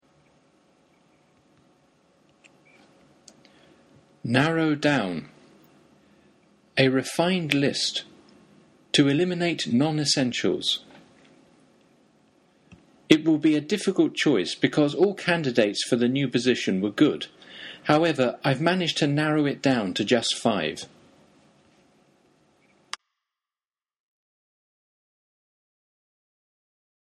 マンツーマン英会話レッスンの担当の英語ネイティブによる発音は下記のリンクをクリックしてください。